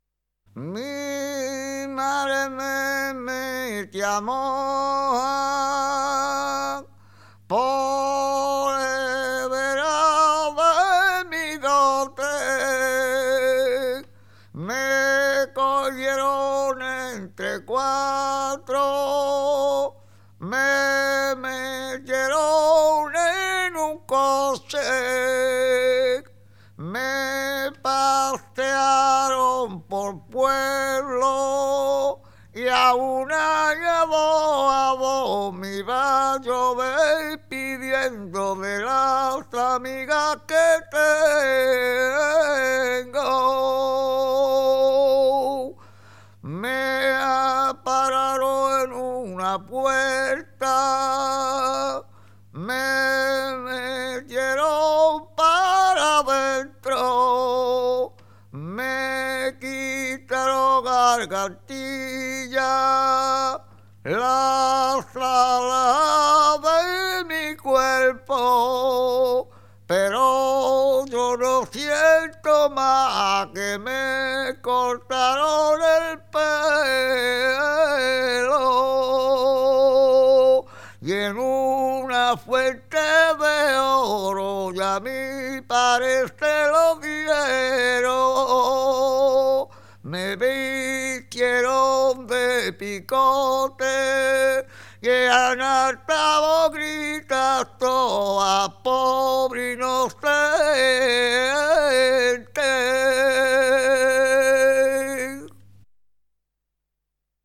II : CHANTS A CAPELLA
1) Romances